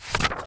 x_enchanting_scroll.6.ogg